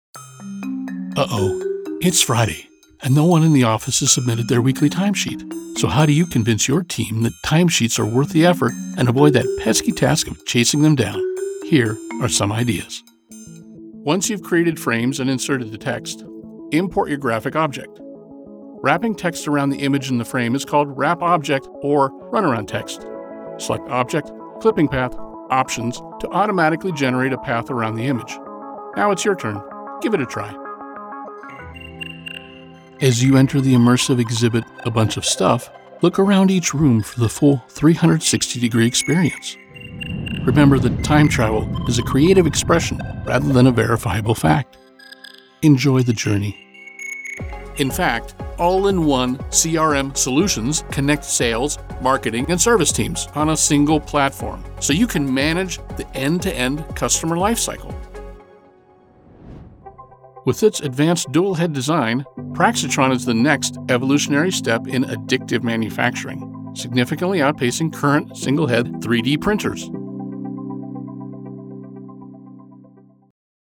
My Narration and Comercial voice is generally described as Trustworthy, Friendly, and Warm.
I can do several accents in addition to "Bay Area California," including most US Northeast, various US Southern, BBC English as Seen on U.S. PBS, and others.
Narration Demo